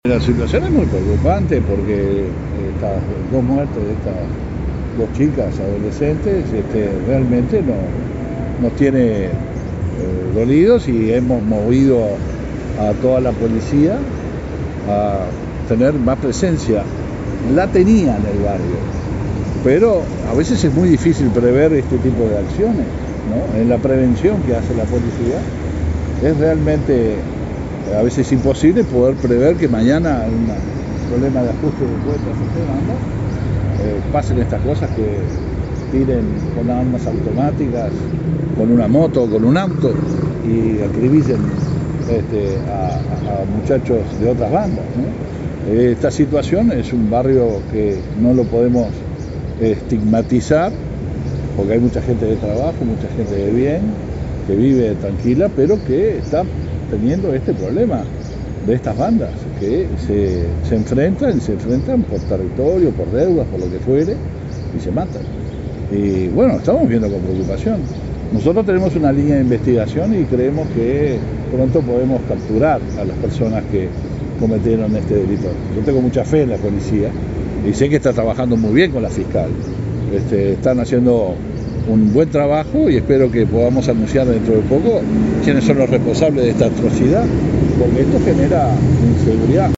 Escuche a Heber aquí: